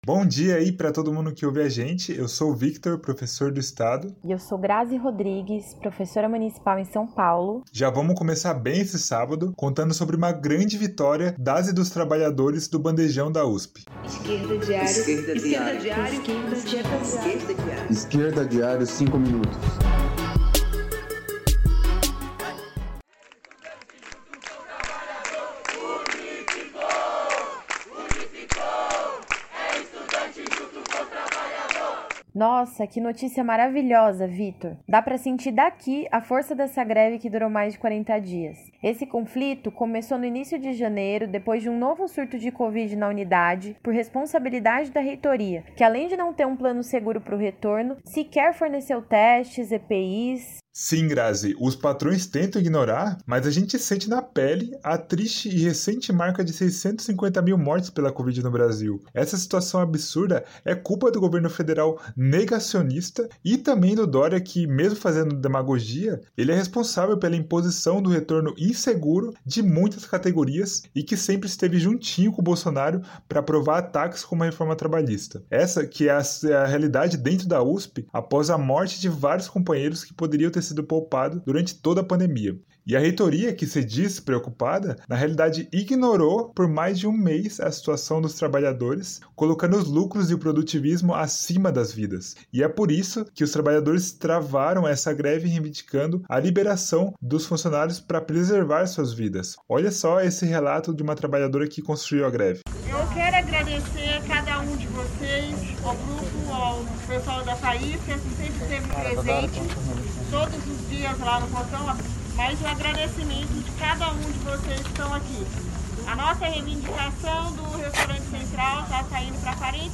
professores da rede pública em São Paulo